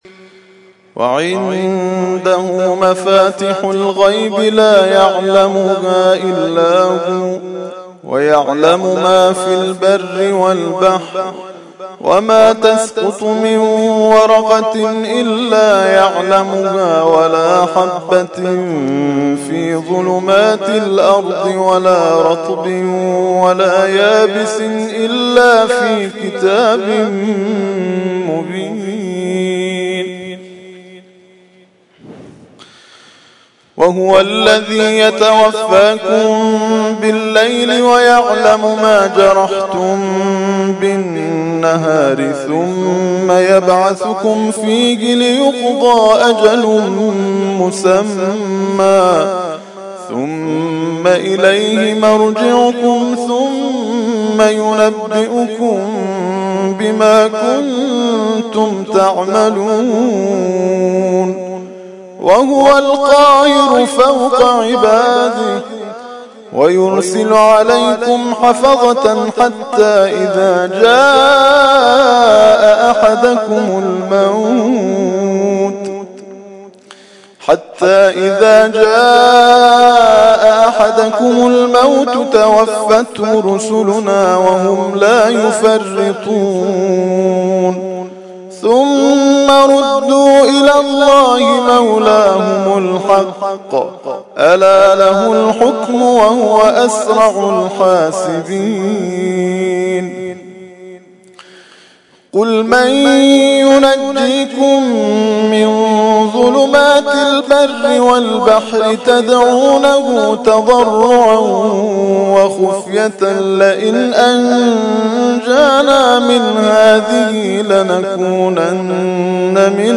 ترتیل خوانی جزء ۷ قرآن کریم در سال ۱۳۹۳